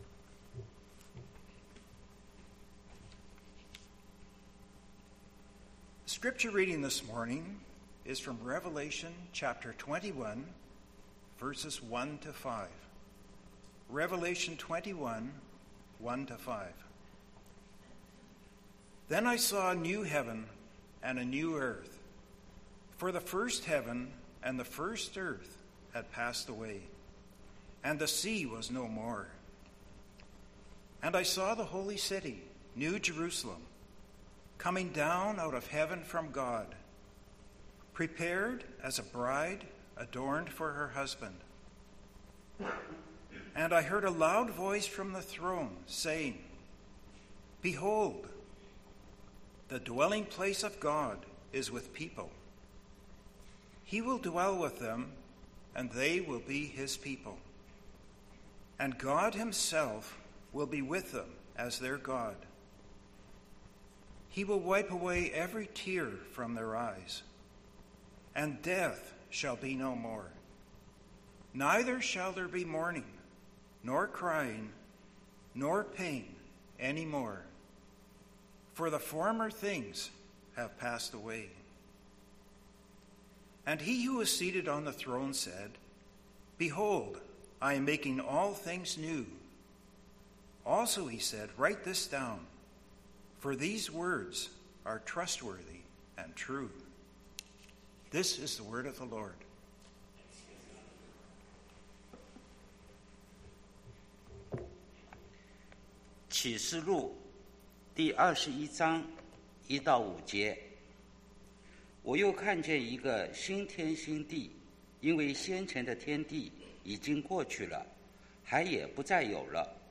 Passage: Revelation 21:1-5 Service Type: 10:30 AM Service « Gospel Rhythms Strength for Today